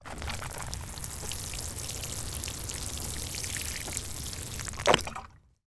Звуки сада
Полив растений пластиковой лейкой 1